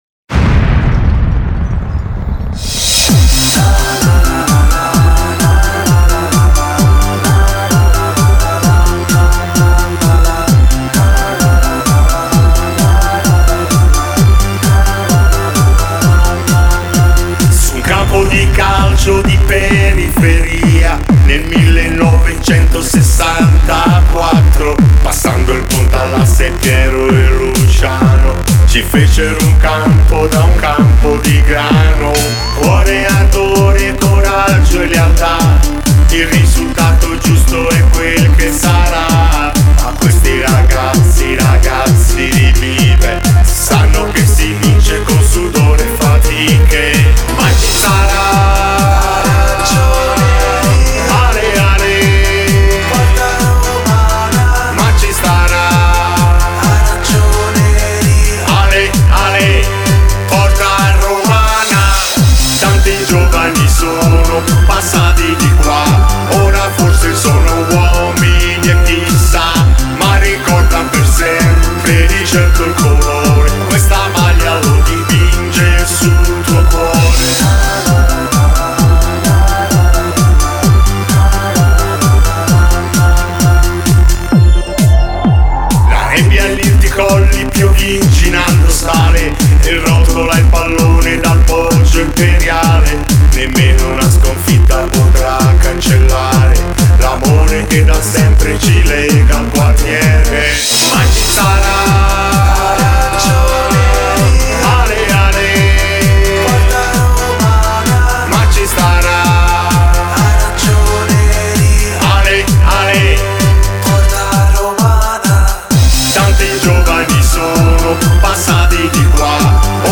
inno